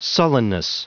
Prononciation du mot sullenness en anglais (fichier audio)
Prononciation du mot : sullenness